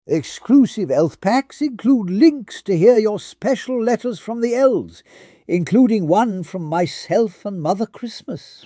Hear The Elves talking